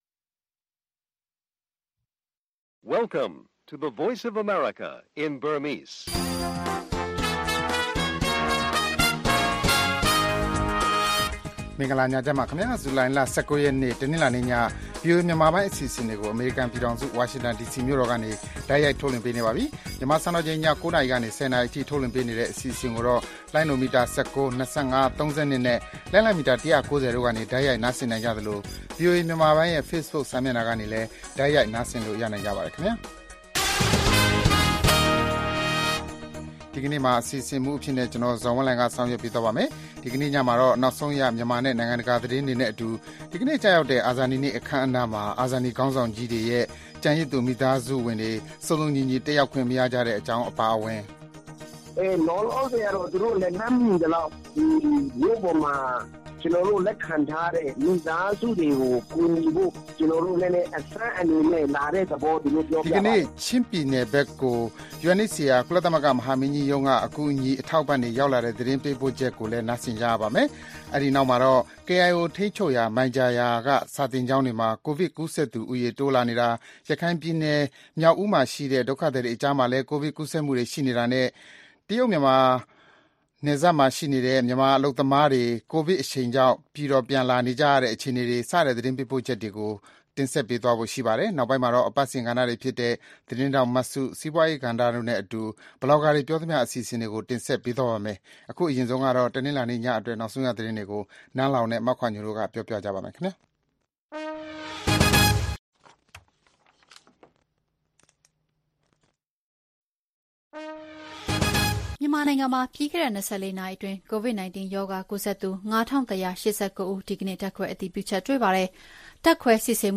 VOA ရေဒီယိုညပိုင်း ၉း၀၀-၁၀း၀၀ တိုက်ရိုက်ထုတ်လွှင့်မှု